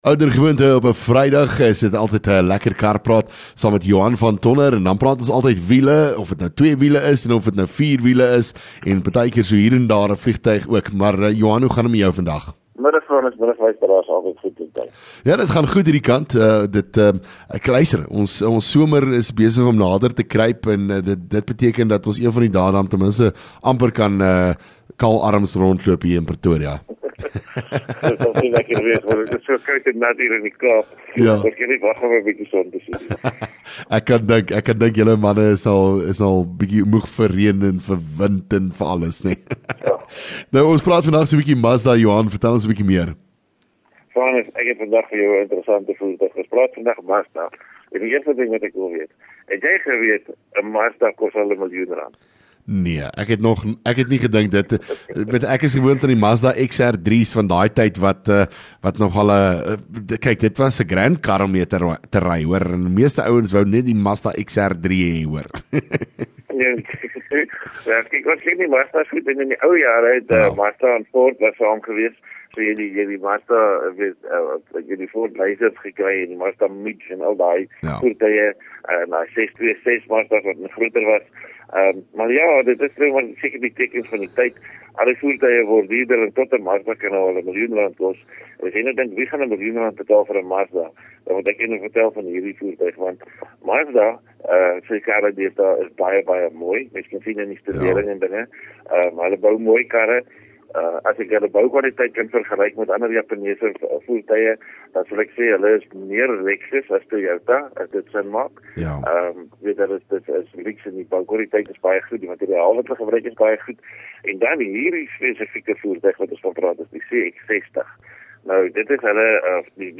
LEKKER FM | Onderhoude 23 Aug Lekker Kar Praat